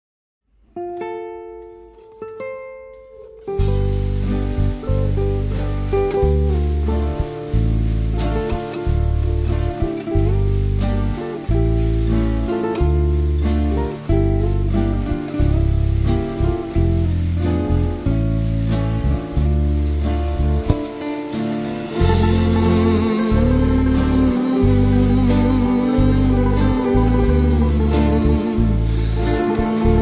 variazione su tema tradizionale rom-russo
• registrazione sonora di musica